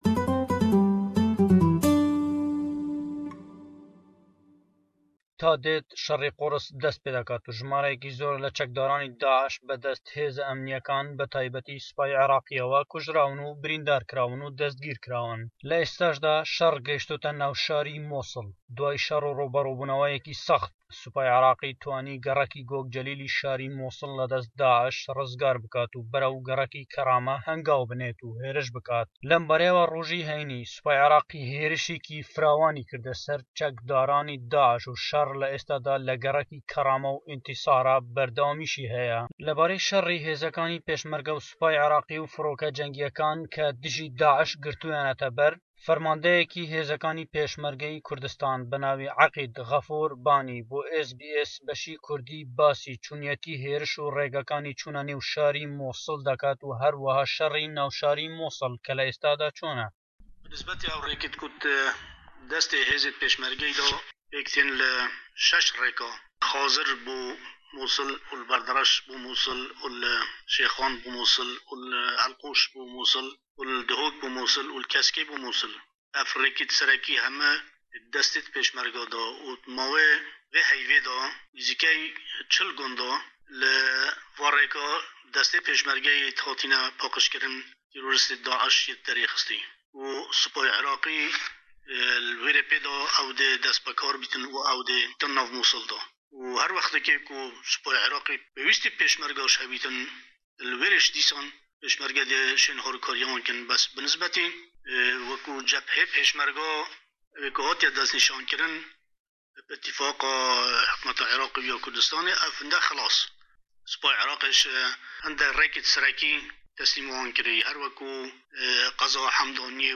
Raportî taybet